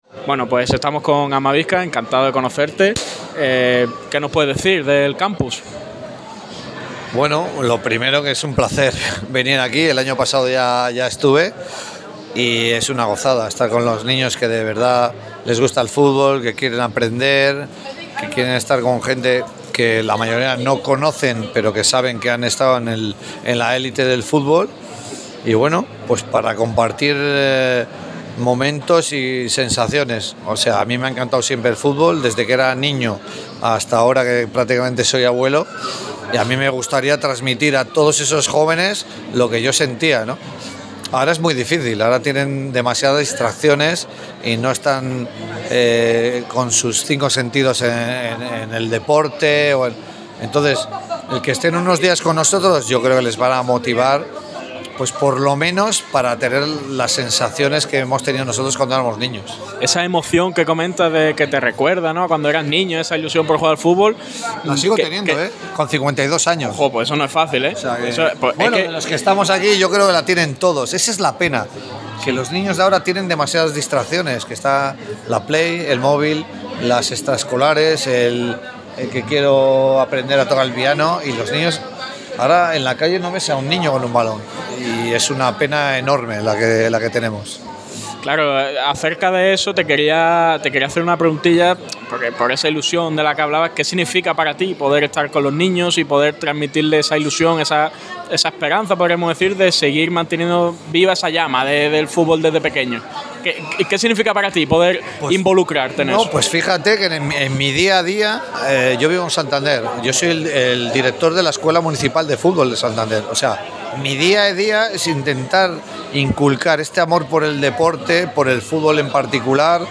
Radio MARCA Málaga ha tenido el placer de entrevistar a varios de los protagonistas, que han dejado algunas pinceladas sobre el Málaga CF. Las leyendas de la selección, se mojan sobre el Málaga CF.